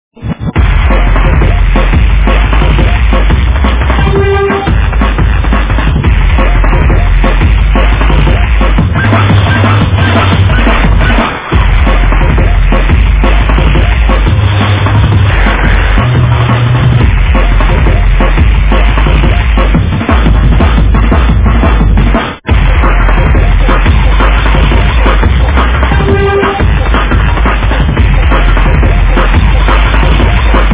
Назад в Drum'n'bass mp3